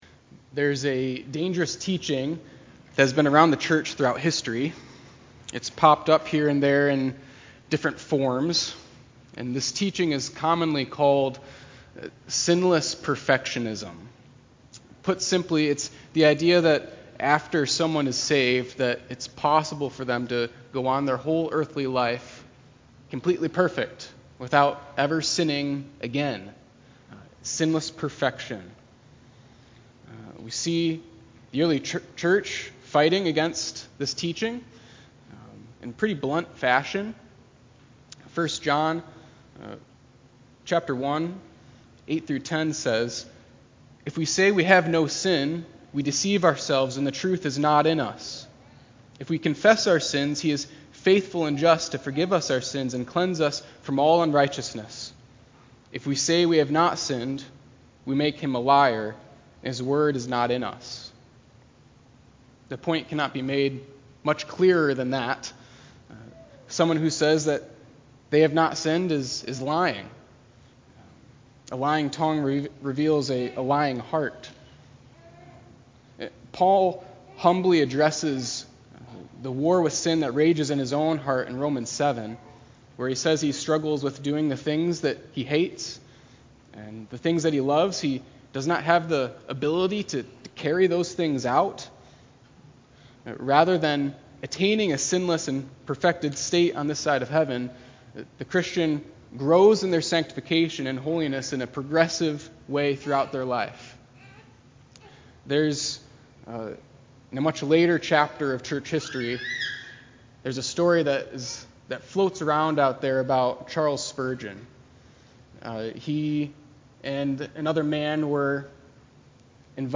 9.11.22-sermon-CD.mp3